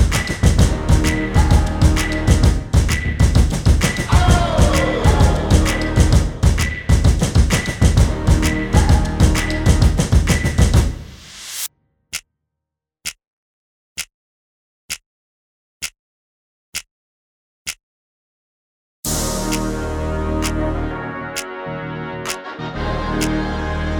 no Backing Vocals Pop (2010s) 3:27 Buy £1.50